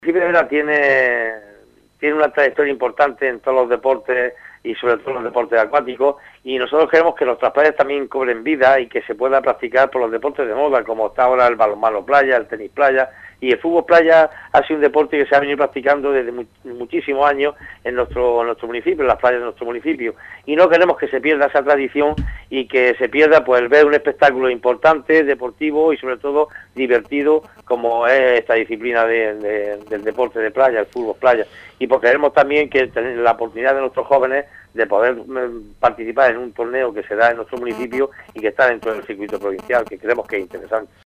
FÉLIX LÓPEZ, ALCALDE VERA- APOYANDO EL TORNEO DE FÚTBOL PLAYA